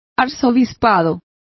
Complete with pronunciation of the translation of archbishopric.